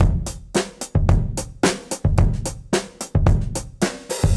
Index of /musicradar/dusty-funk-samples/Beats/110bpm
DF_BeatA_110-01.wav